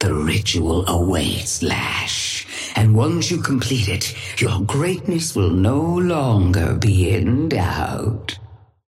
Sapphire Flame voice line - The ritual awaits, Lash. And once you complete it, your greatness will no longer be in doubt.
Patron_female_ally_lash_start_03.mp3